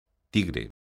tigre_son.mp3